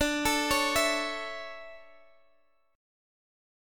DM7sus2 Chord